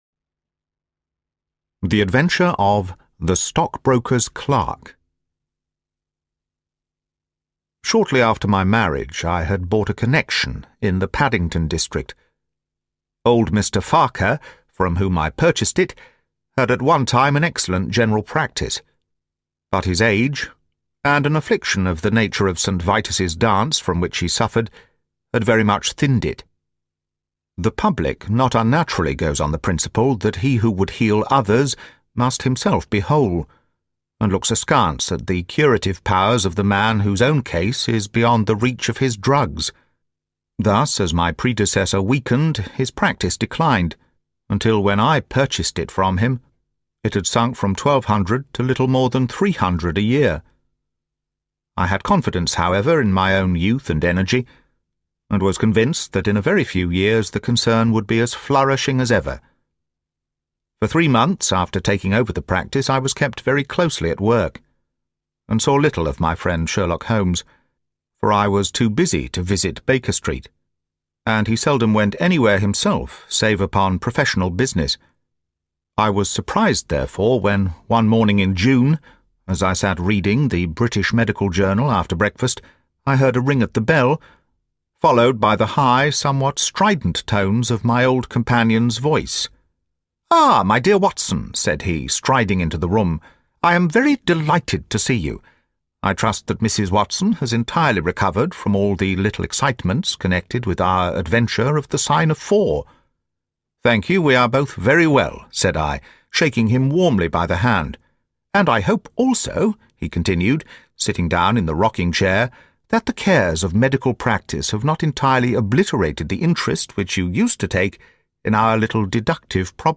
The Red-Headed League: Secrets, Deception, and Mystery (Audiobook)